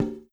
Percussion #06.wav